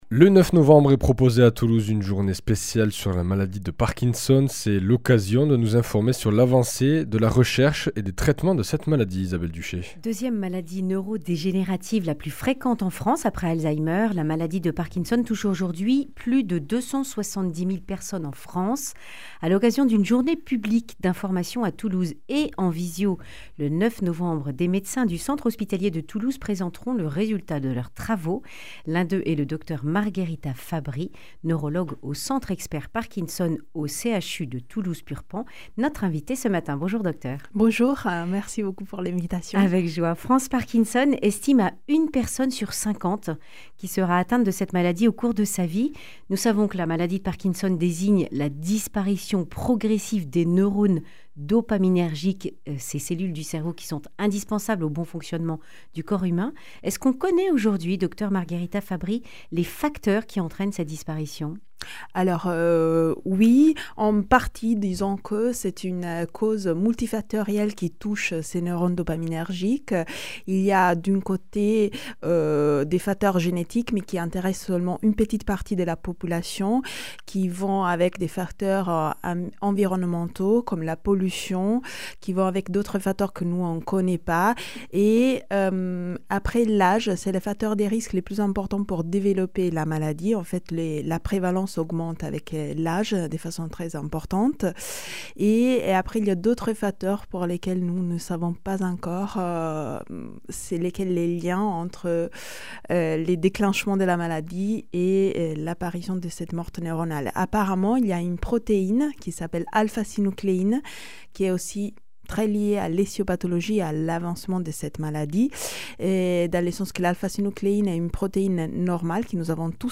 Accueil \ Emissions \ Information \ Régionale \ Le grand entretien \ Parkinson, où en est-on ?